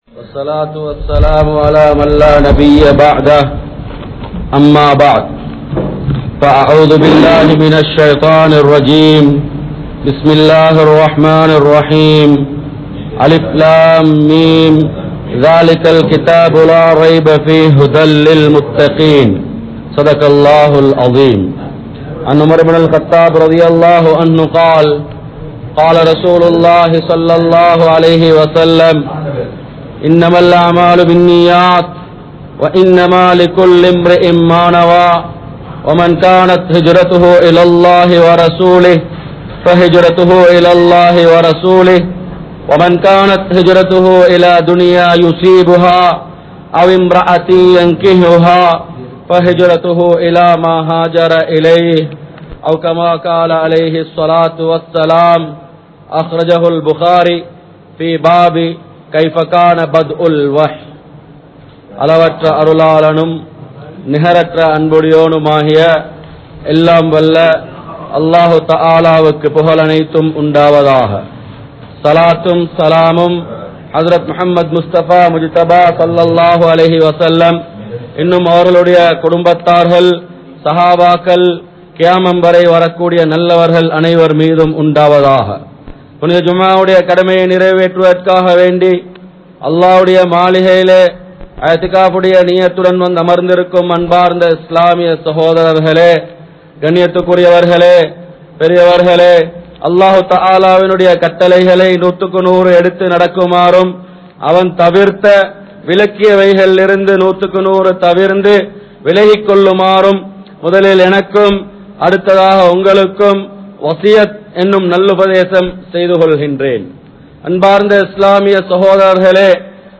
Al Quranil Ungalukku Enna Illai? (அல்குர்ஆனில் உங்களுக்கு என்ன இல்லை?) | Audio Bayans | All Ceylon Muslim Youth Community | Addalaichenai
Kollupitty Jumua Masjith